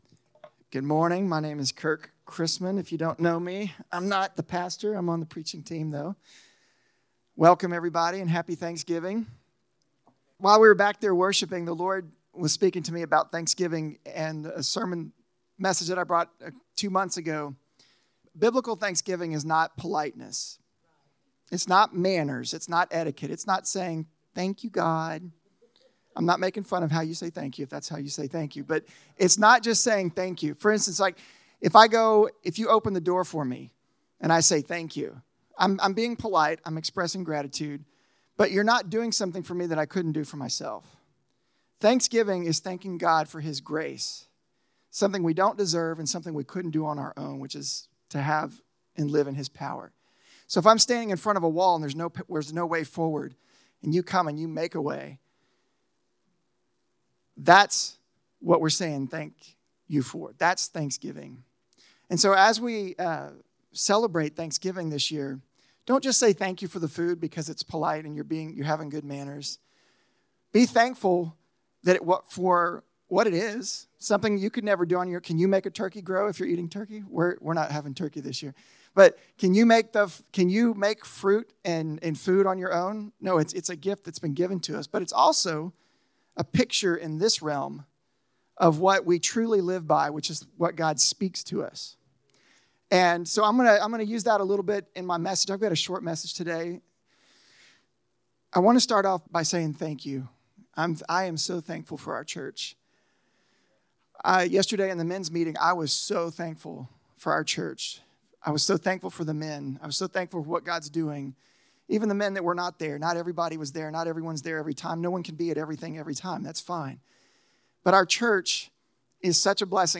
The Secret of Perspective – SERMONS